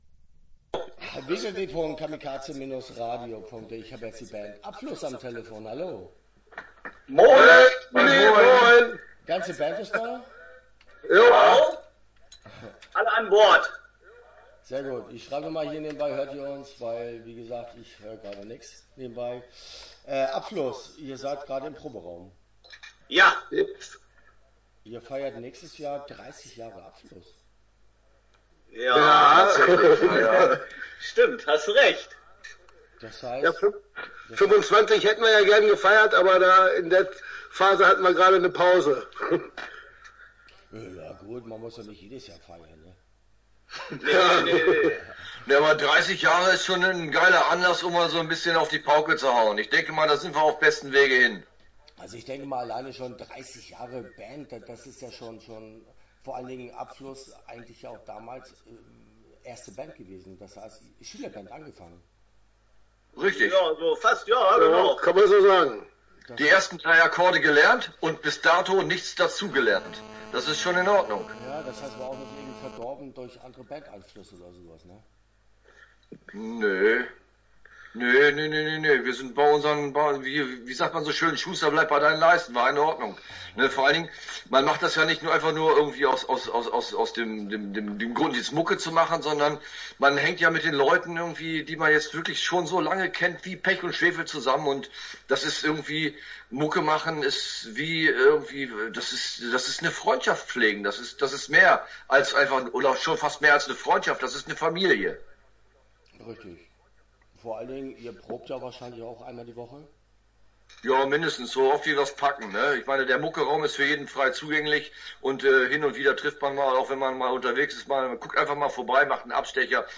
Abfluss - Interview Teil 1 (10:20)